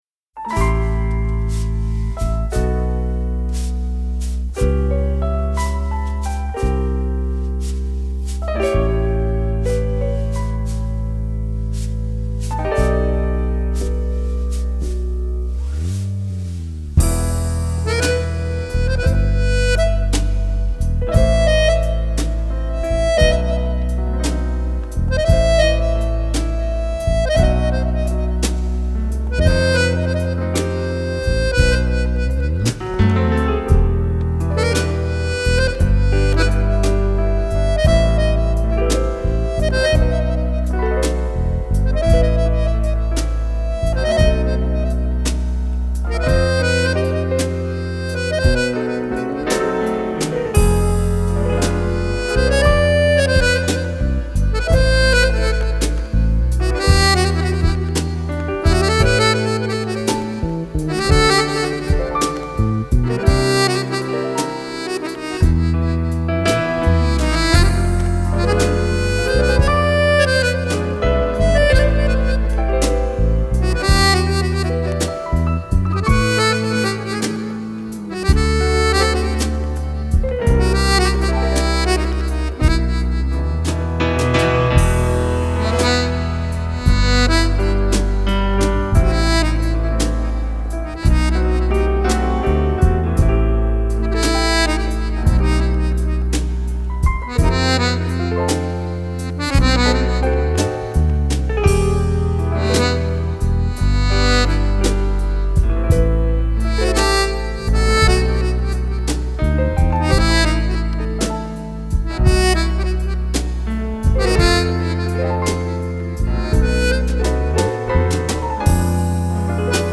Azeri Folk